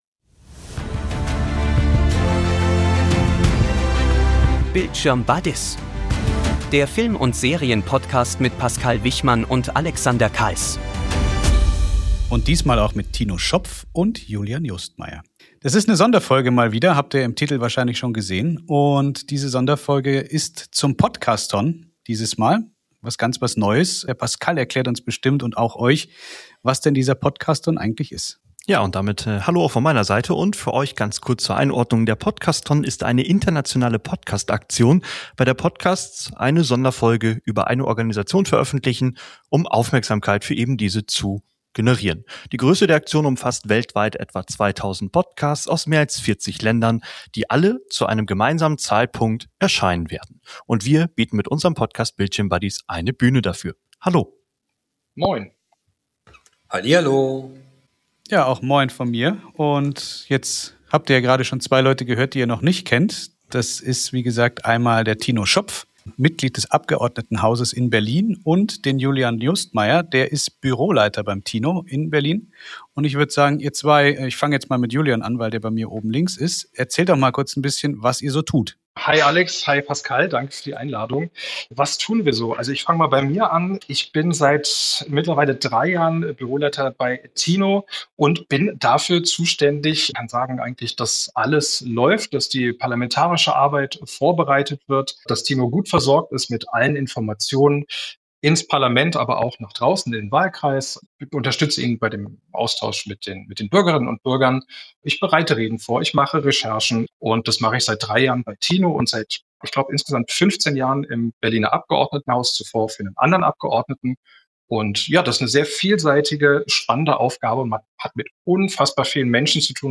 In dieser Episode des Bildschirm Buddies Podcasts diskutieren wir im Rahmen der internationale Aktion "Podcasthon" mit Tino Schopf, Mitglied des Abgeordnetenhauses in Berlin